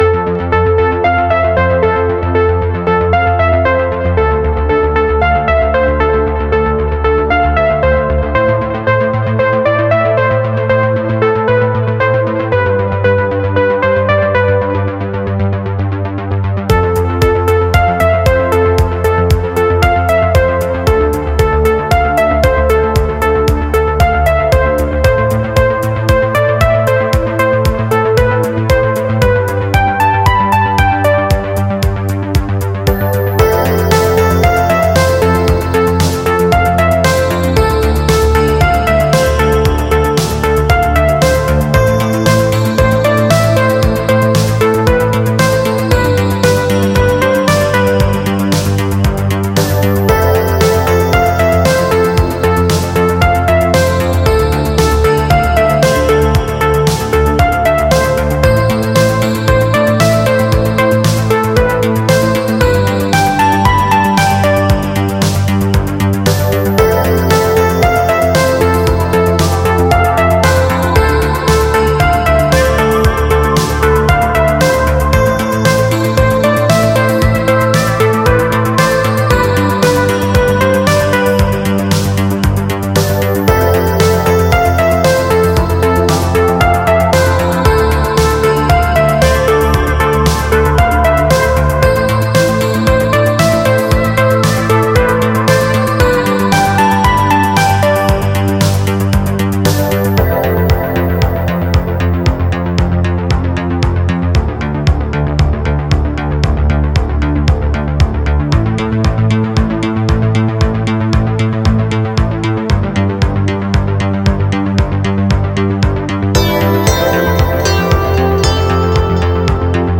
Жанр: Synthwave, Retrowave, Spacewave, Dreamwave, Electronic